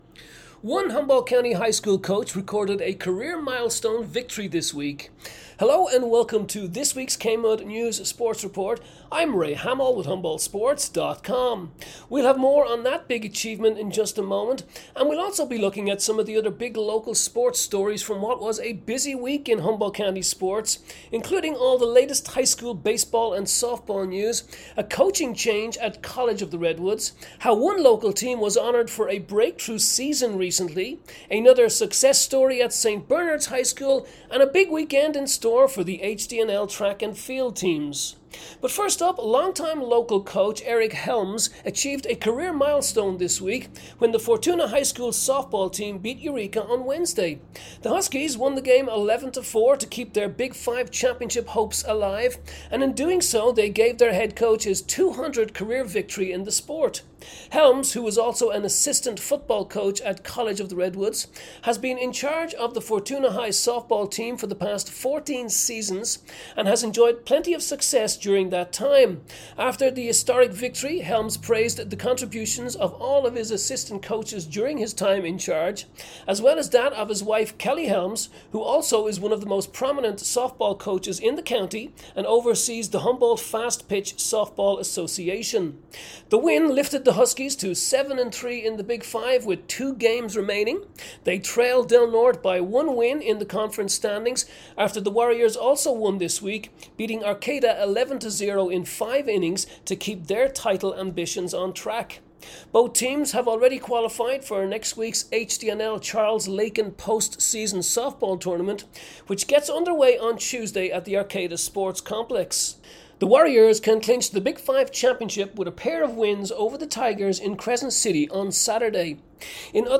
May 2 KMUD Sports Report